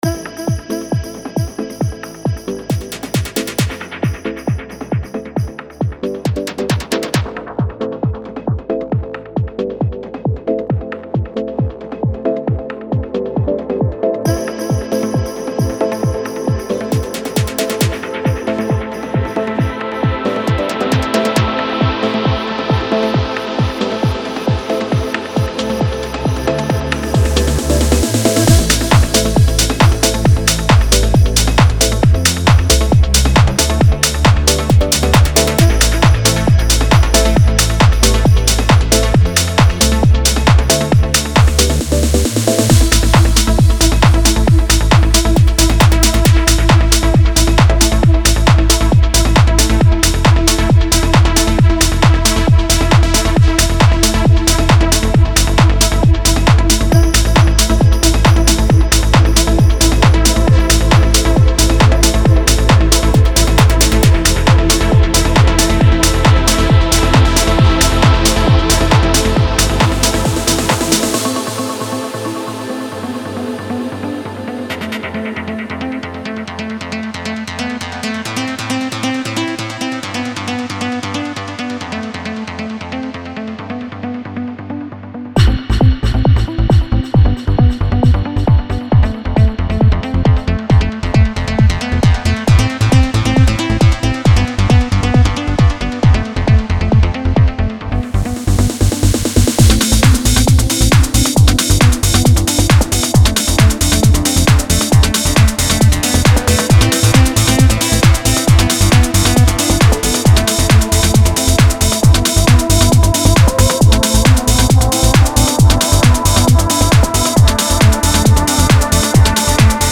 Genre:Trance
世代全体のダンスミュージックを定義したメロディ、パッド、ゲート駆動のプログレッションに飛び込みましょう。
広がりがあり、メロディックで、ユーフォリック、そして大きなフロアとさらなる高揚感のために作られています。
デモサウンドはコチラ↓
135 BPM